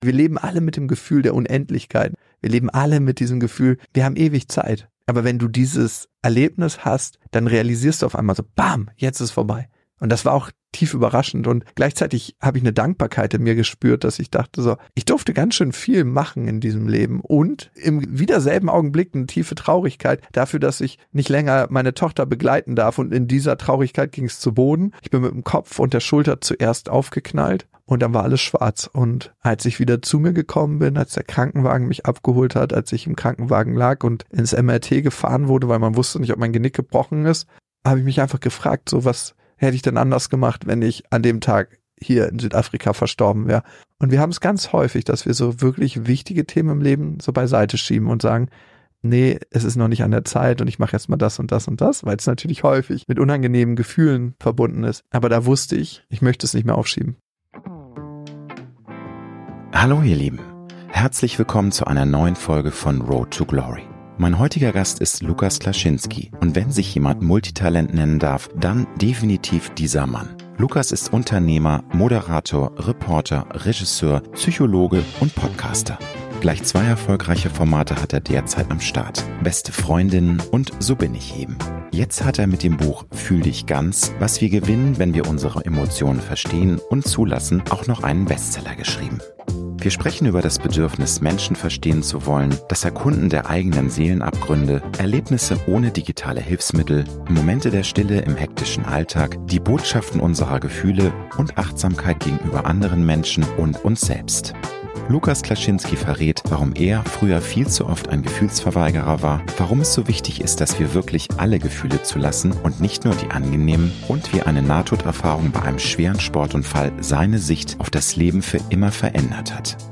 ~ Road to Glory - Promi-Talk